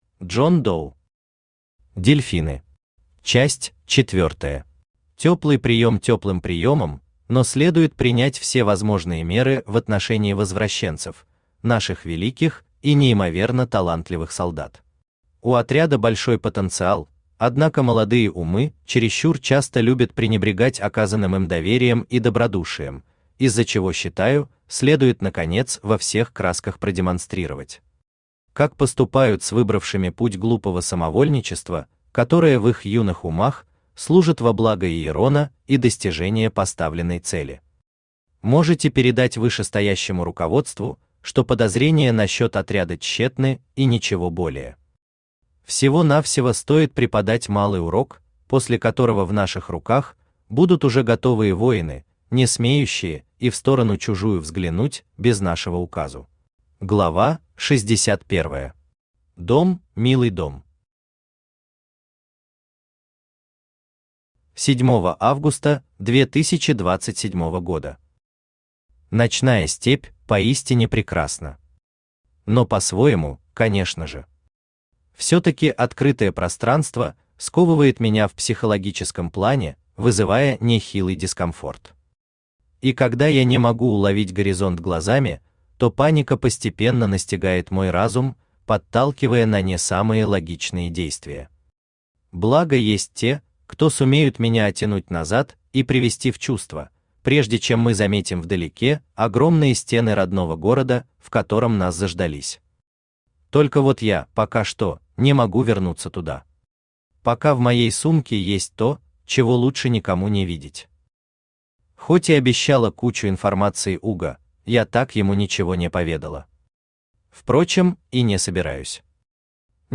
Аудиокнига Дельфины. Часть четвёртая | Библиотека аудиокниг
Часть четвёртая Автор Джон Доу Читает аудиокнигу Авточтец ЛитРес.